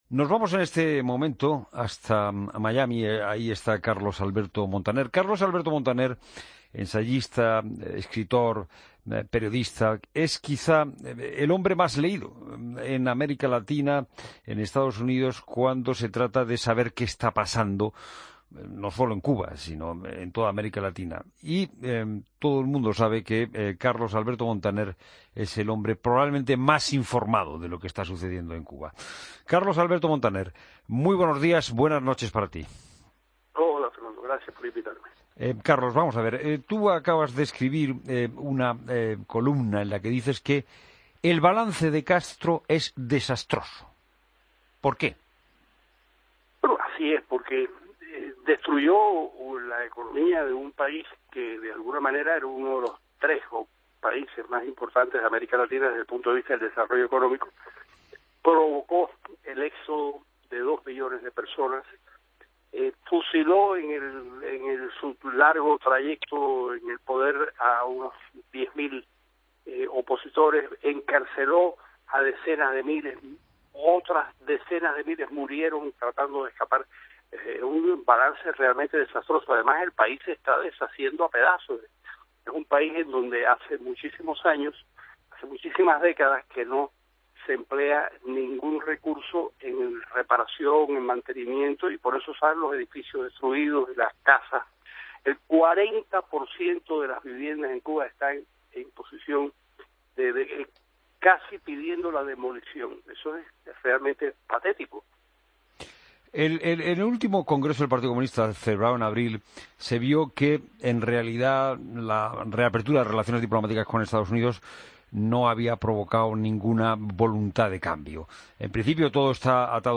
AUDIO: Entrevista al escritor cubano Carlos Alberto Montaner